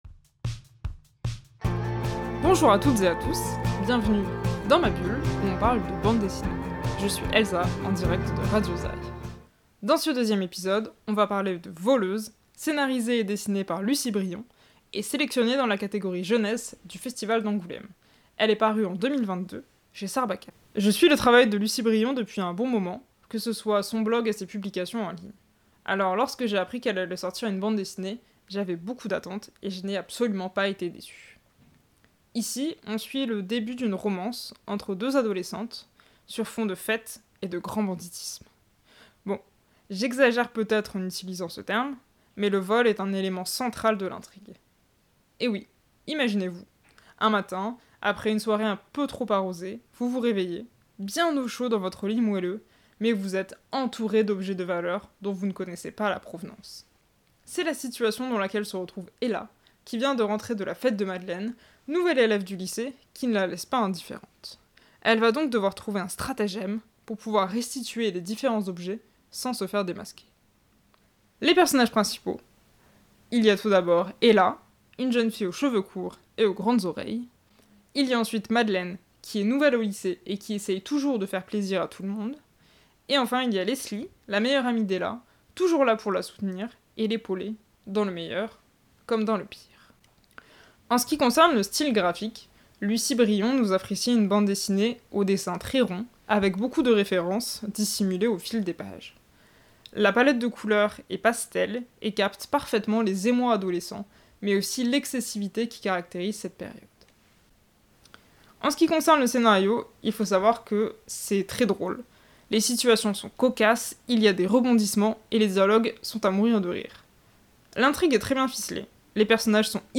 Cette chronique (qui se veut) hebdomadaire vous parle, en quelques minutes, d’une œuvre ou d’une série de livres.